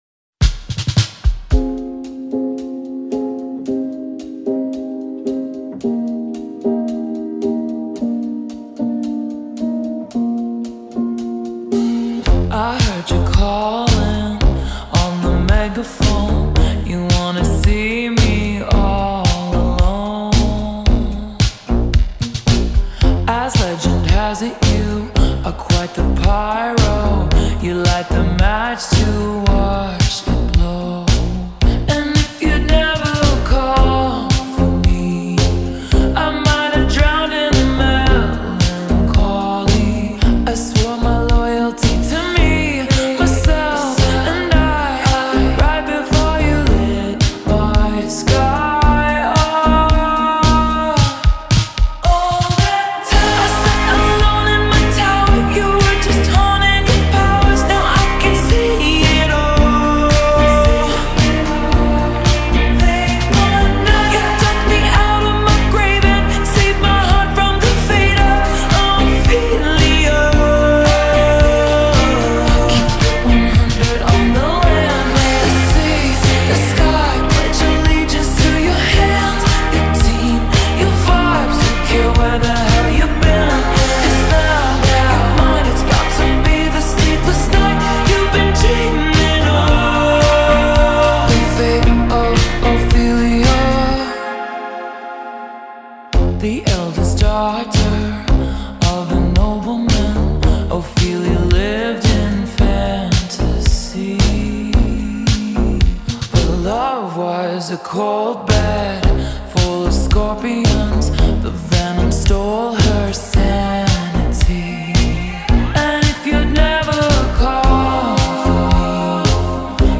نسخه Slowed مناسب ادیت های احساسی